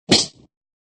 効果音 ブチッ
sei_ge_himo_kire02.mp3